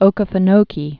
(ōkə-fə-nōkē, -kē-)